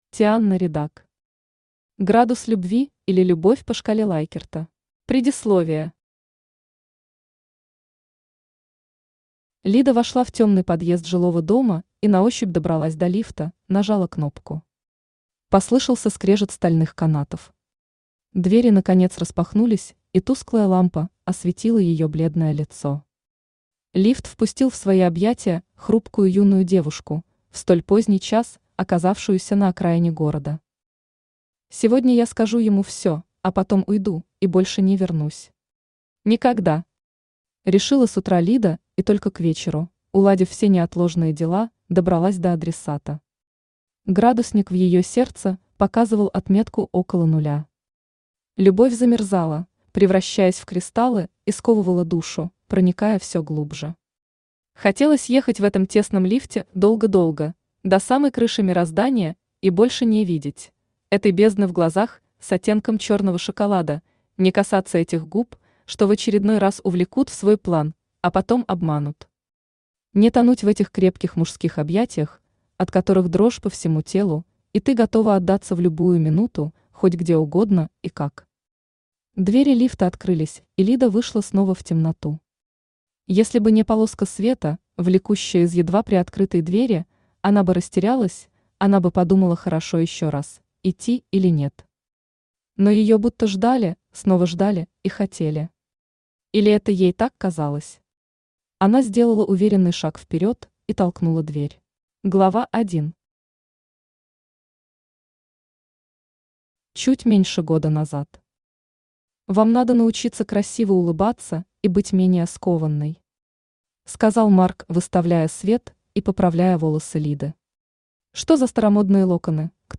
Аудиокнига Градус любви или любовь по шкале Лайкерта | Библиотека аудиокниг
Aудиокнига Градус любви или любовь по шкале Лайкерта Автор Тианна Ридак Читает аудиокнигу Авточтец ЛитРес.